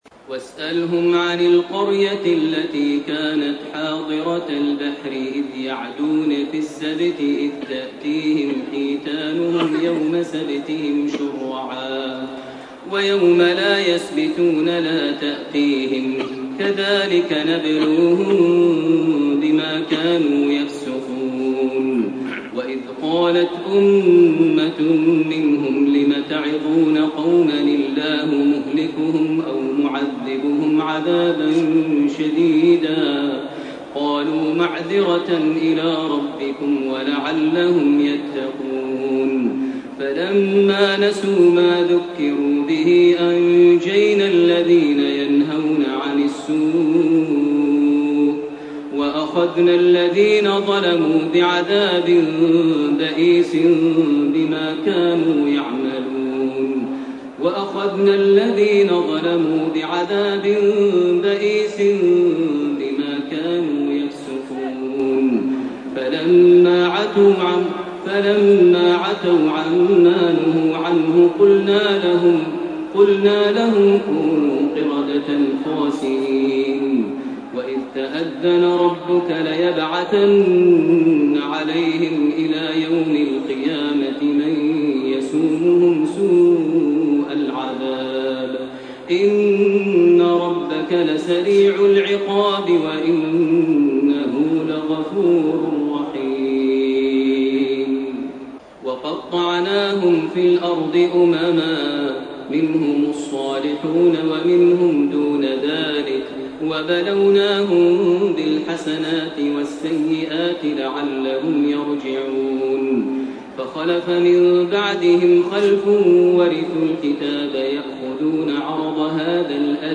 سورتي الأعراف من 193 الي آخرها وسورة الأنفال من 1 - 36 > تراويح ١٤٣٢ > التراويح - تلاوات ماهر المعيقلي